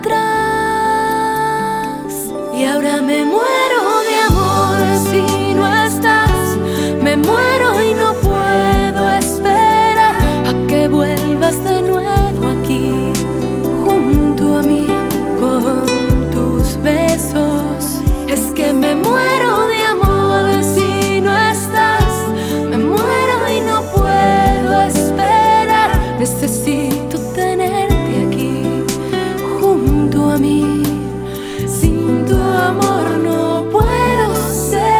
Скачать припев
Pop Latino